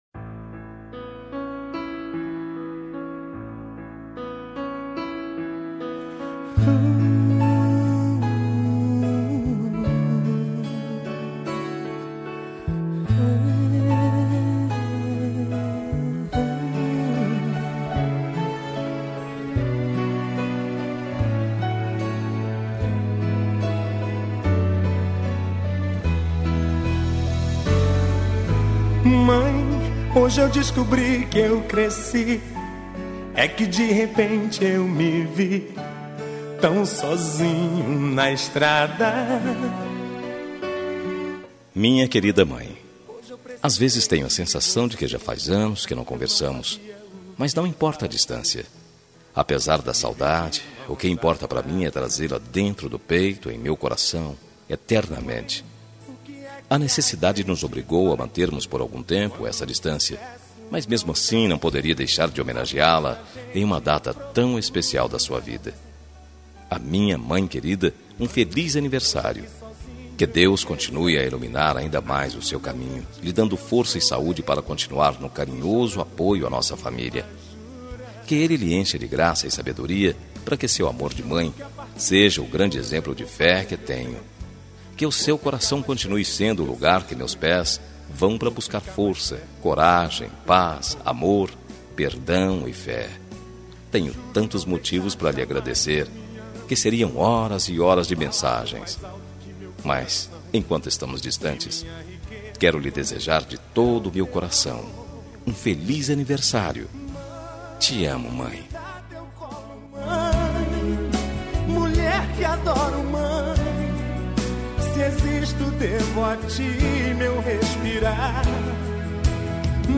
Telemensagem de Aniversário de Mãe – Voz Masculina – Cód: 1451 – Distante